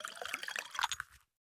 PixelPerfectionCE/assets/minecraft/sounds/item/bottle/fill3.ogg at ca8d4aeecf25d6a4cc299228cb4a1ef6ff41196e